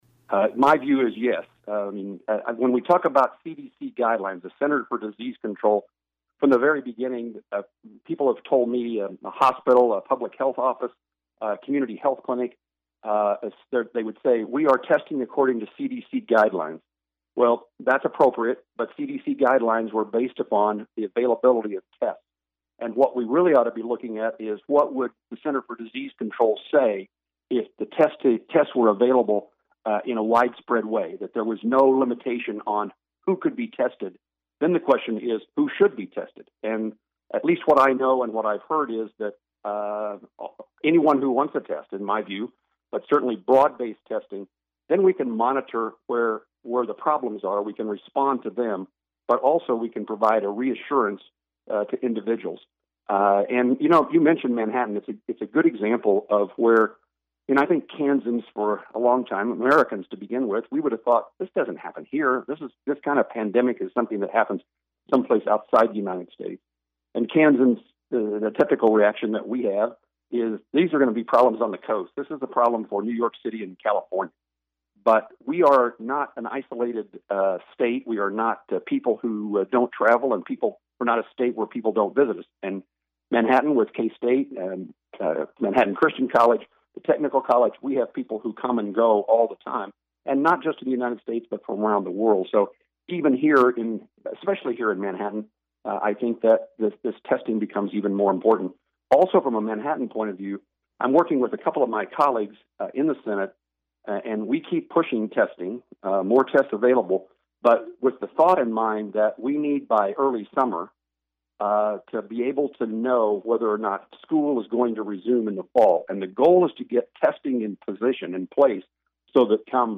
COVID-19 Q & A with U.S. Senator Jerry Moran